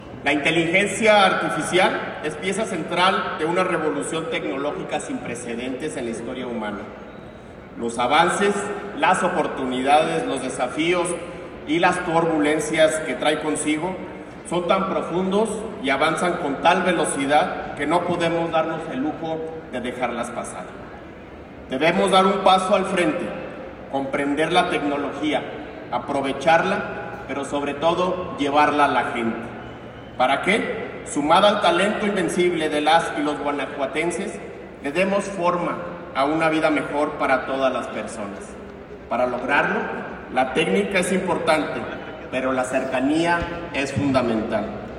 Jorge Daniel Jiménez Lona, secretario de gobierno